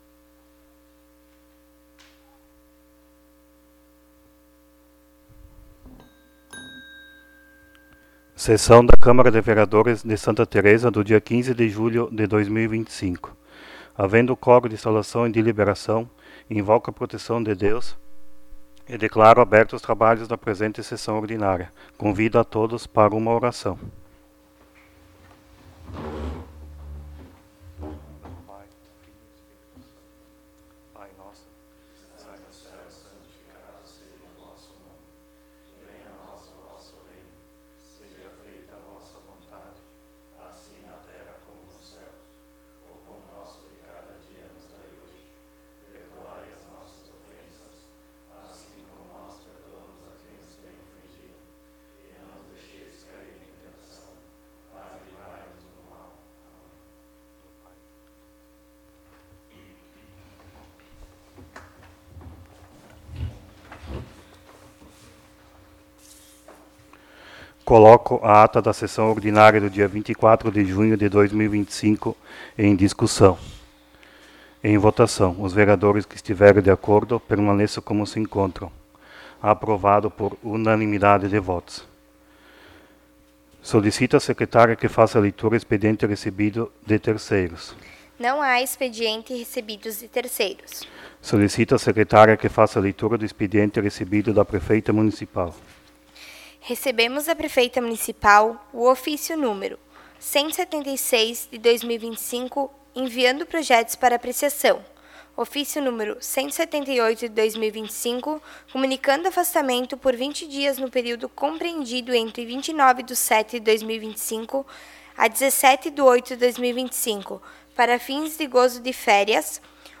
11° Sessão Ordinária de 2025
Áudio da Sessão